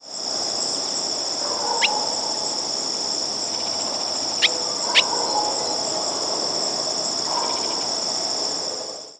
Barn Swallow diurnal flight calls
"Vwit" calls from bird in flight.